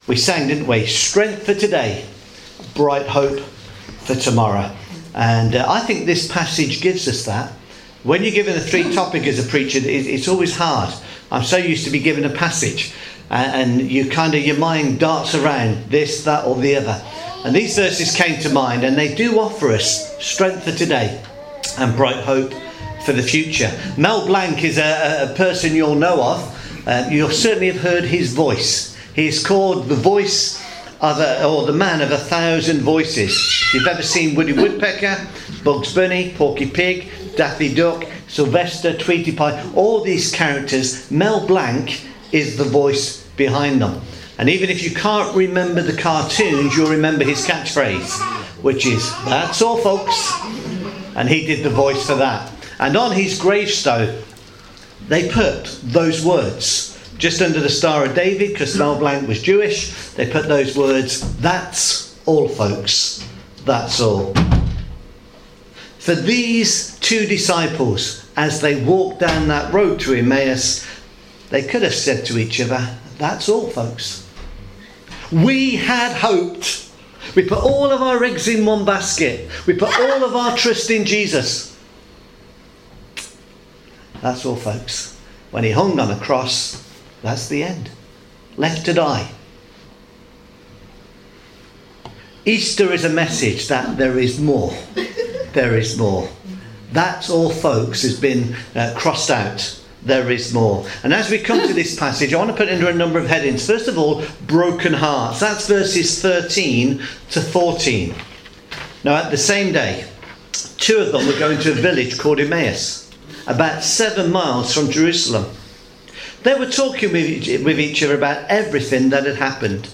Luke chapter 24 verses 13-31 – sermon